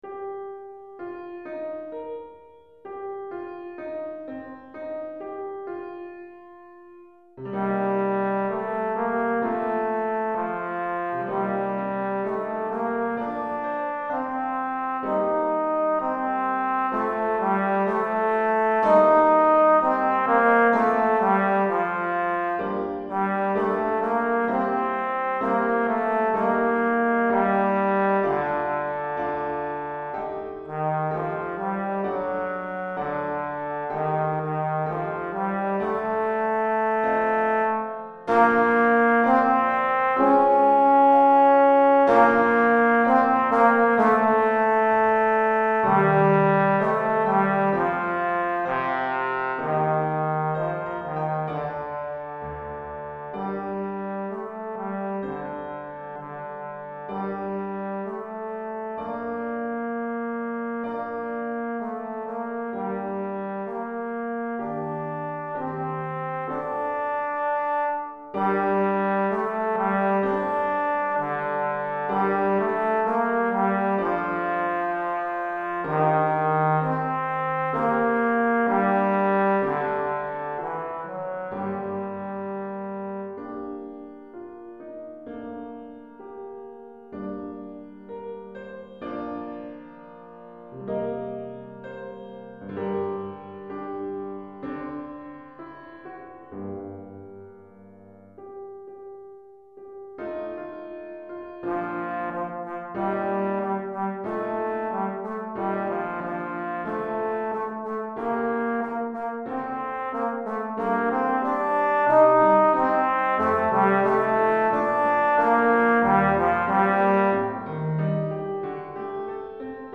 pour trombone et piano DEGRE CYCLE 1 Durée